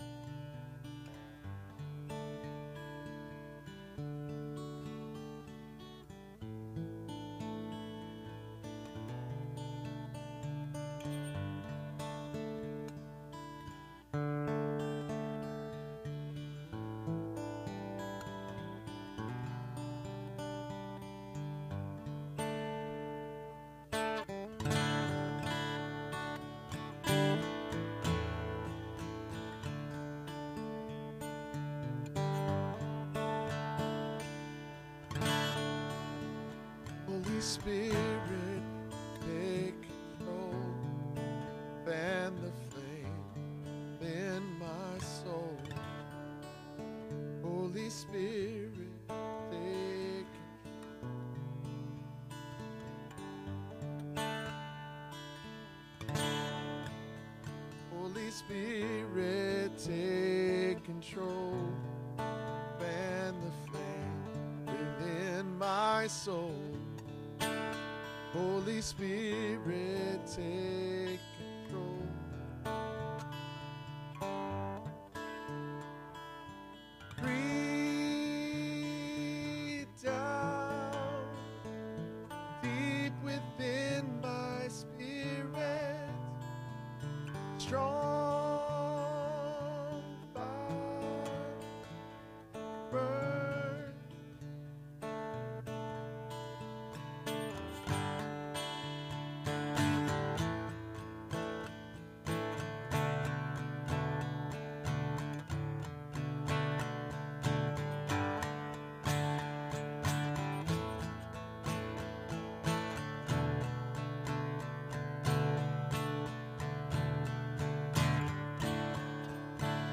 SERMON DESCRIPTION Isaac’s family, though chosen by God, faced deep struggles—infertility, sibling rivalry, and parental favoritism—yet God's faithfulness remained constant.